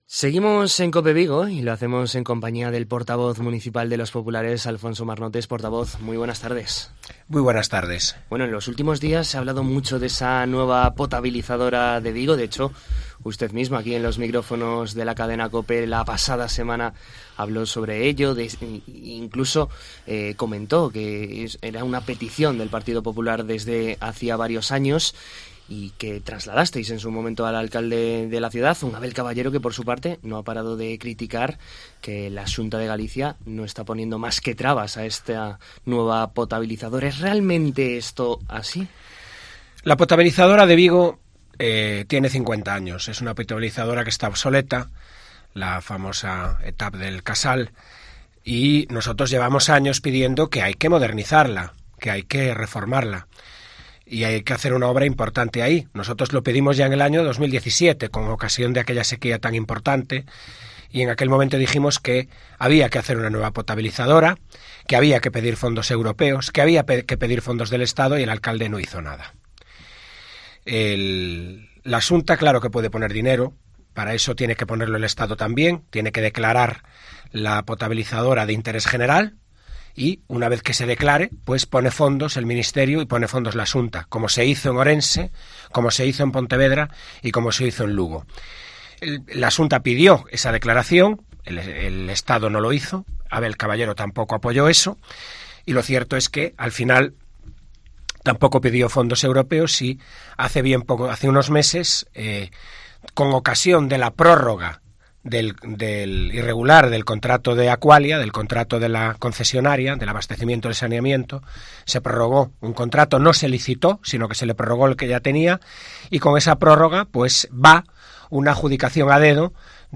En COPE Vigo conocemos la actualidad municipal de manos del portavoz del PP de Vigo